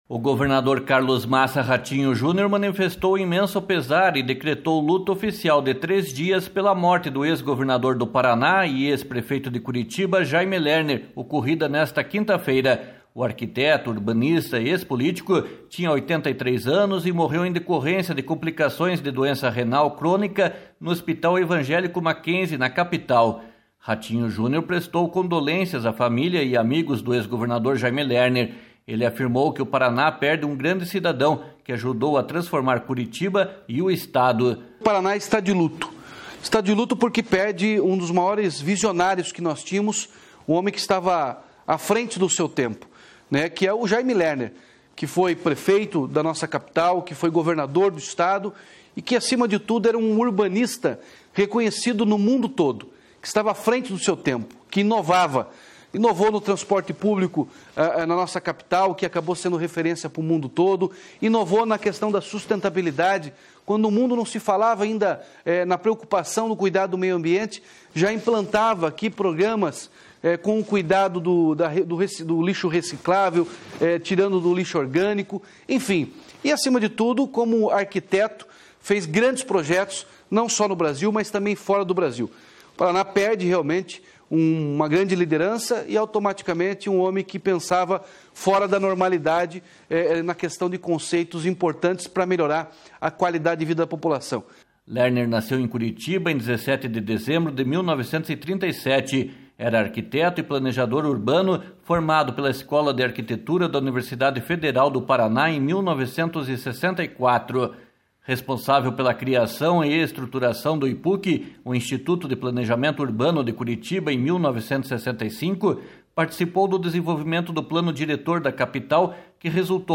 //SONORA RATINHO JUNIOR//